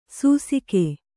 ♪ sūsike